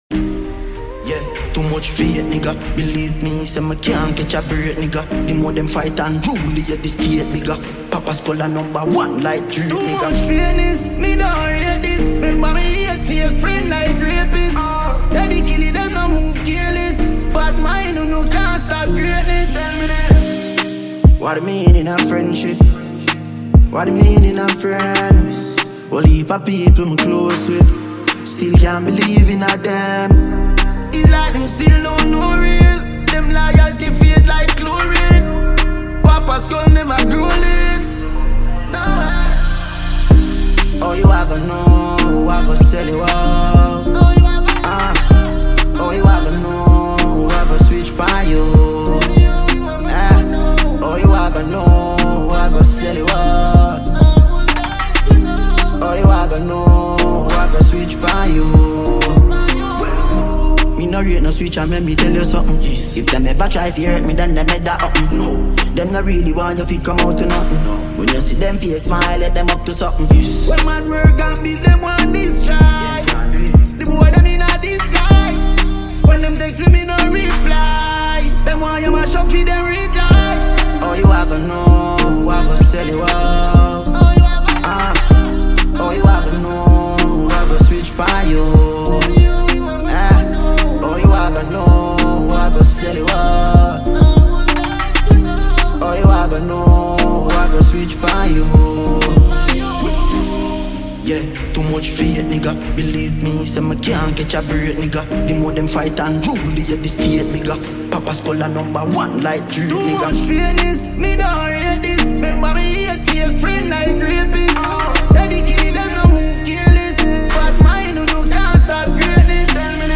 global dancehall super-star
With catchy beats and infectious rhythms
smooth vocals and charismatic delivery